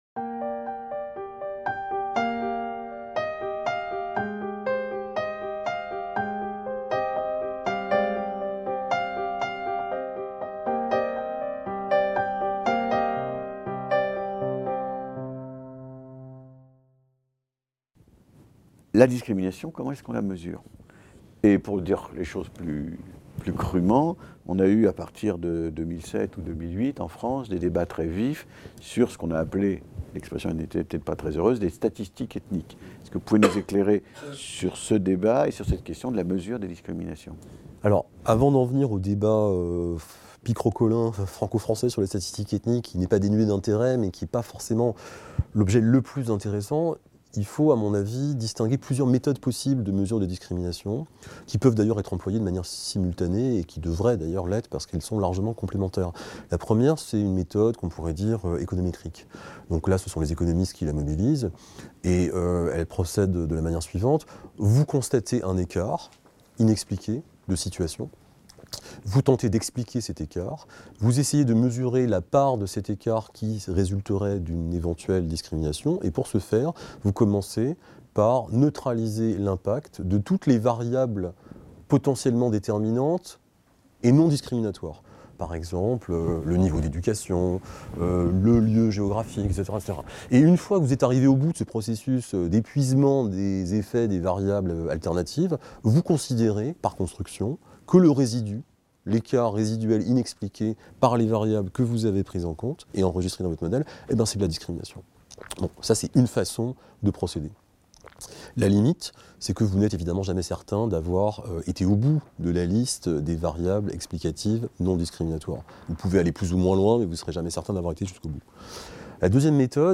Discriminations - Un entretien